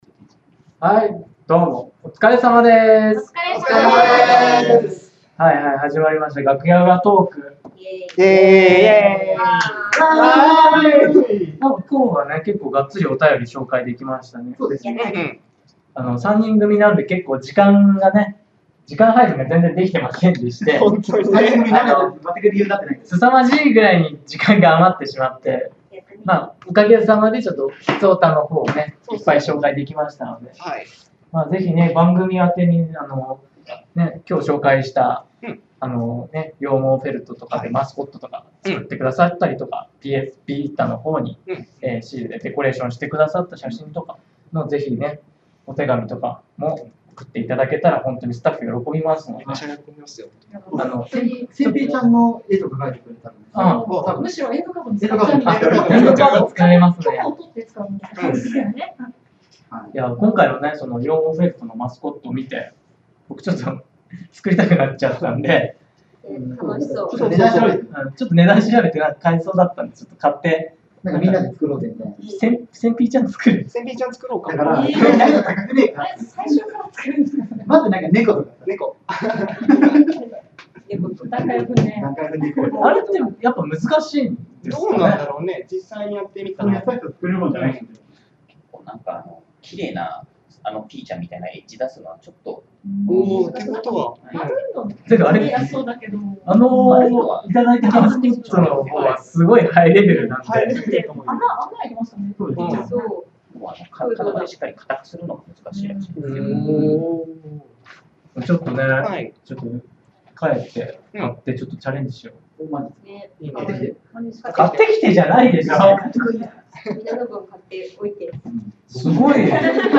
男だけのむさ苦しい配信だったでしょう。
さてさてと、まずは「楽屋裏トーク」からですね。